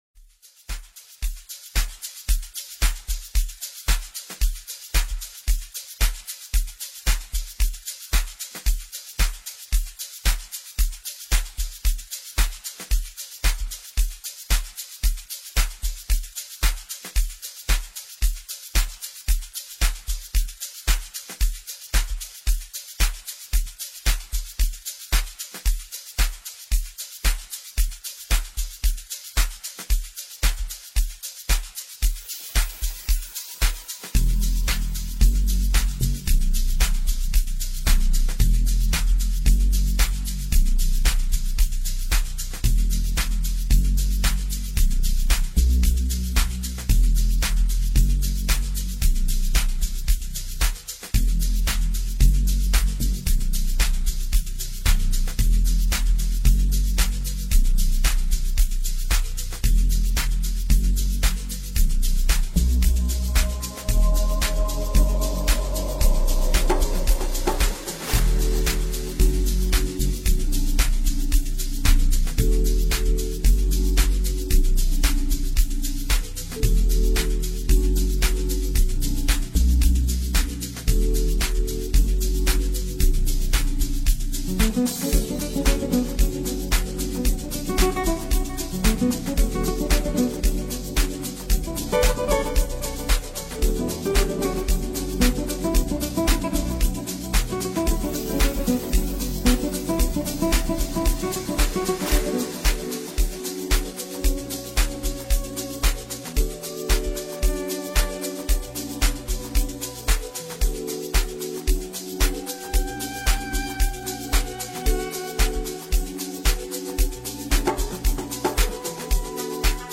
melodic production
Amapiano